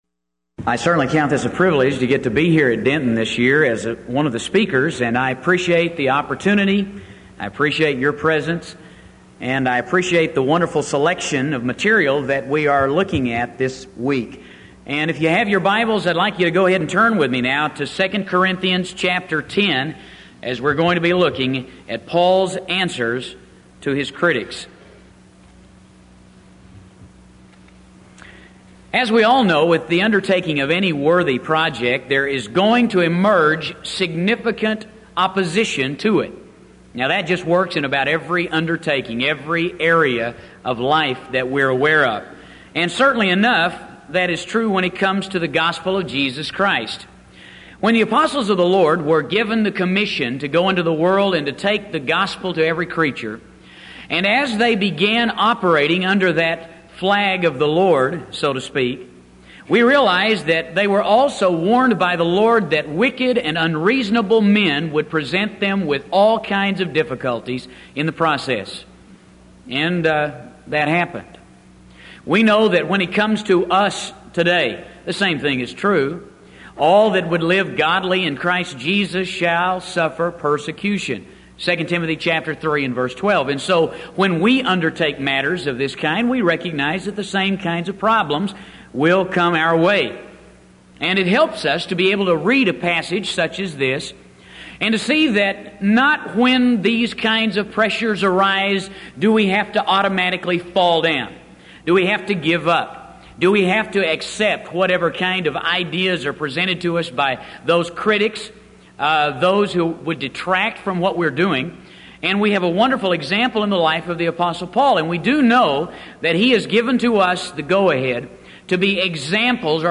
Event: 1989 Denton Lectures Theme/Title: Studies In The Book Of II Corinthians